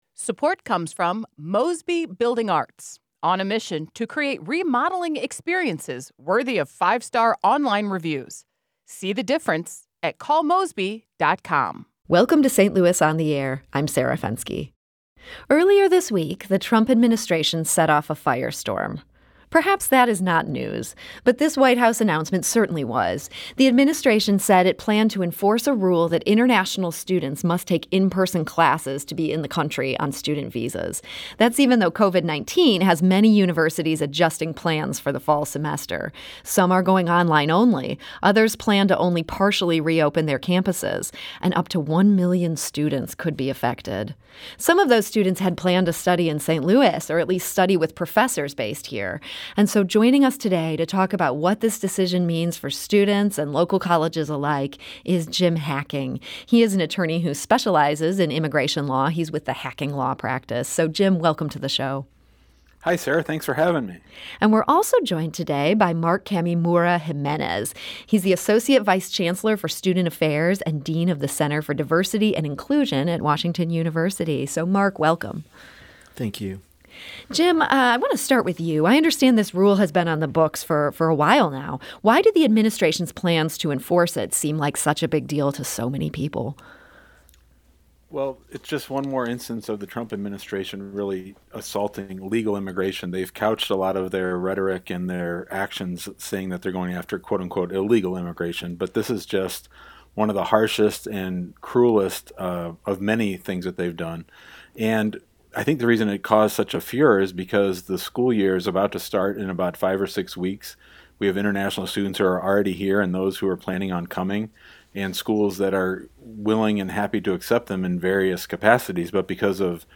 Click here to listen to the interview on St. Louis on the Air